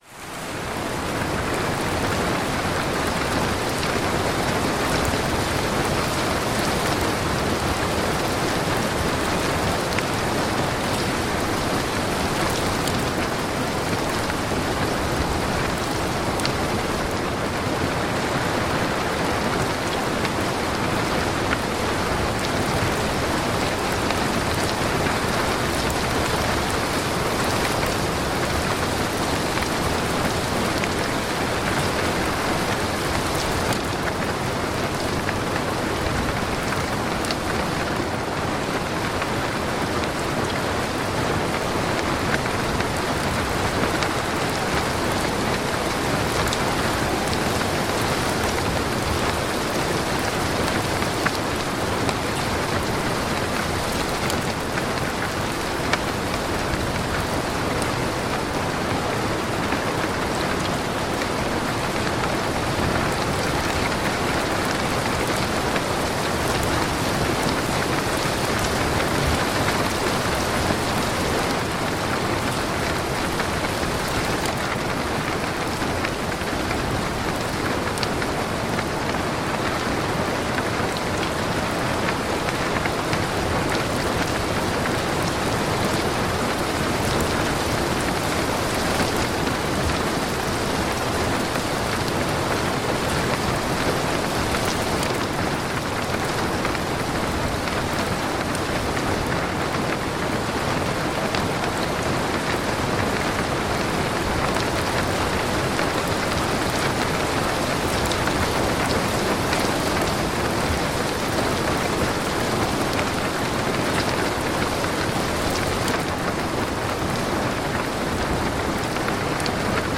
Lluvia y Trueno en Refugio Natural para Dormir sin Luchar ni Desvelarte
Sonido de Lluvia, Lluvia Relajante, Lluvia Suave, Lluvia Nocturna, Descanso Con Lluvia